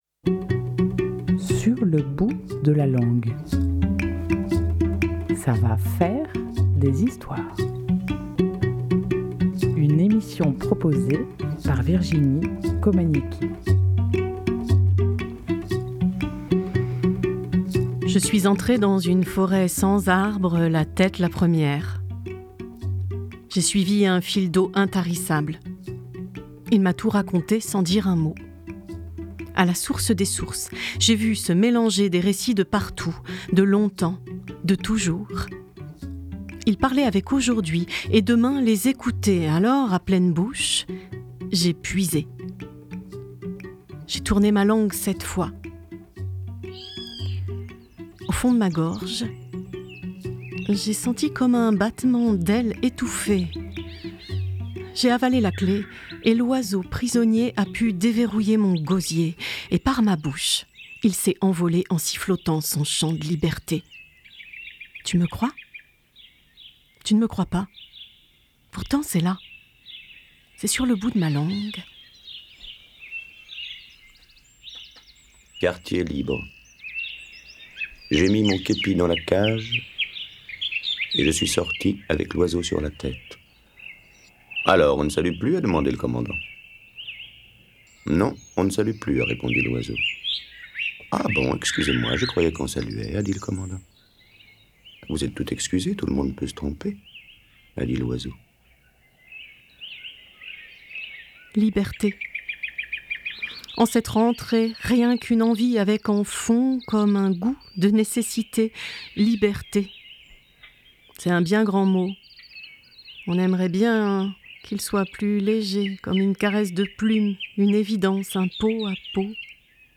Chants des femmes Joggis dans le désert du Thar (Inde)